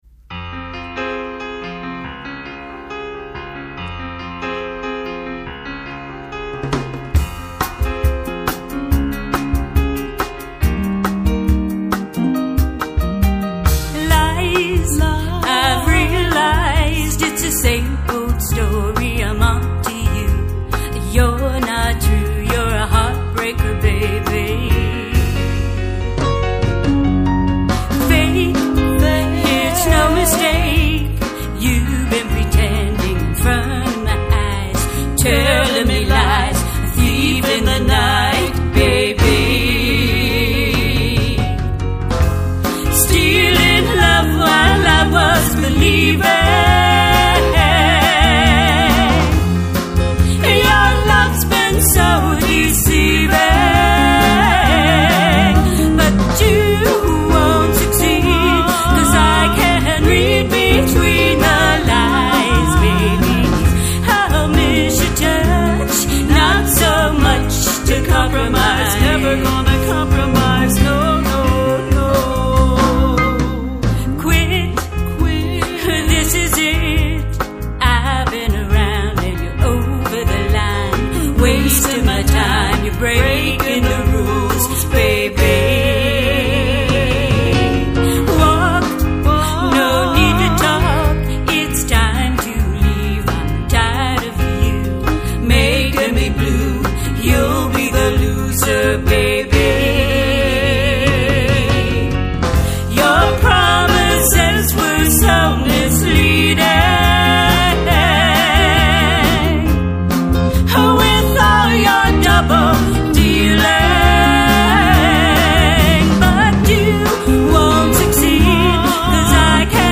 our folk/rock ballad